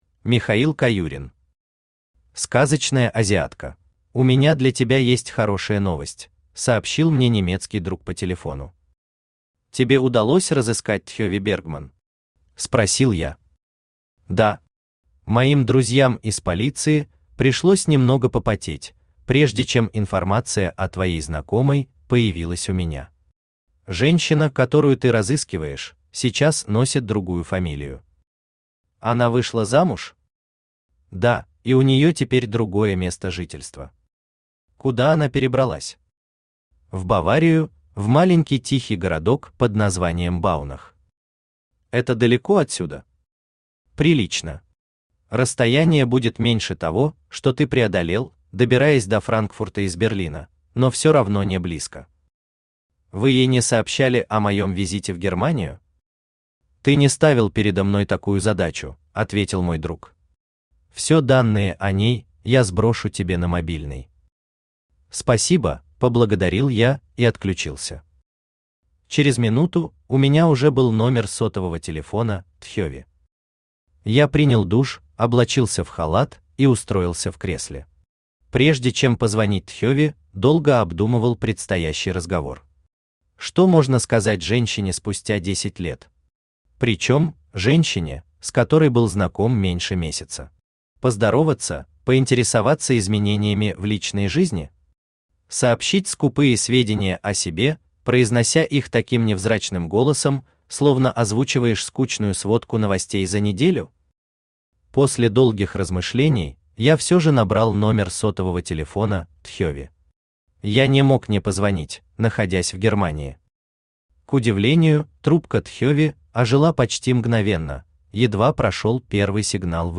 Aудиокнига Сказочная азиатка Автор Михаил Александрович Каюрин Читает аудиокнигу Авточтец ЛитРес.